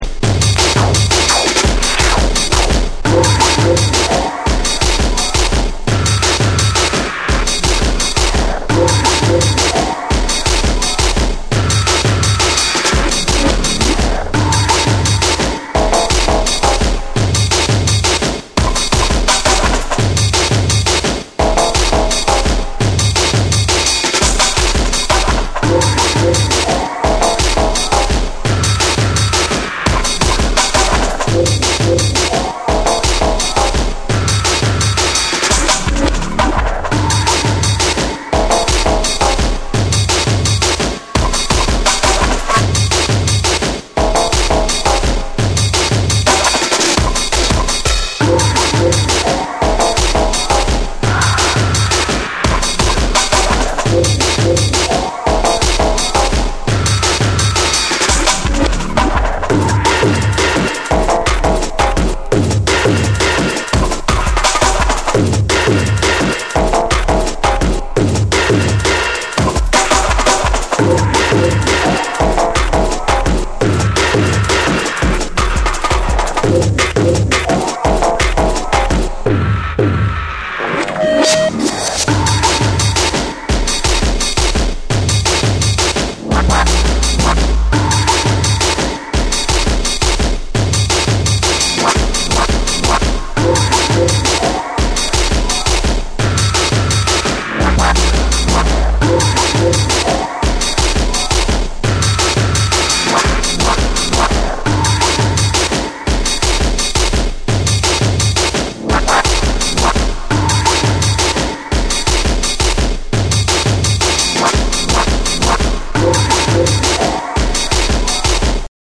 緻密に構築されたリズムと冷ややかな空間処理が際立つ
JUNGLE & DRUM'N BASS